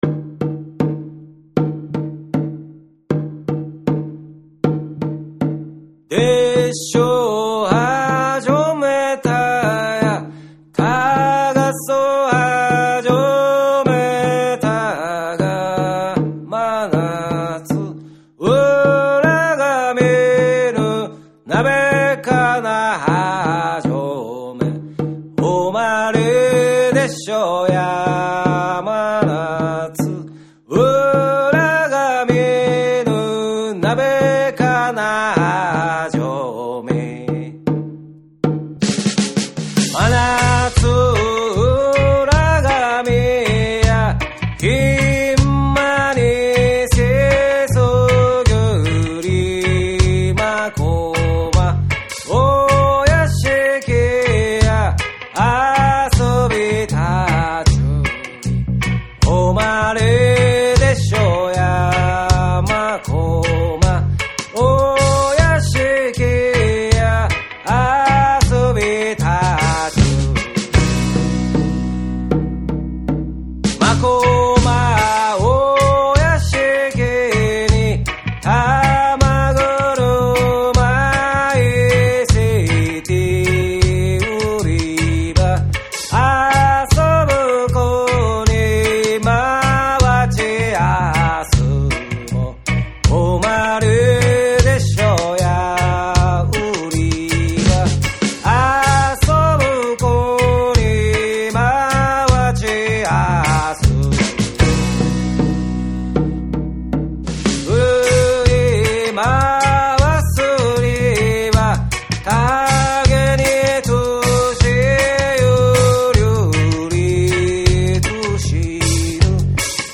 横浜・月桃荘で録音されたこの楽曲は、シンプルながら胸に響く力強い仕上がりに！
チヂン太鼓の独特なリズムと島唄の歌声が、徐々にDubbyでReggaeでJazzyな世界観へと展開していく。
JAPANESE / REGGAE & DUB / NEW RELEASE(新譜)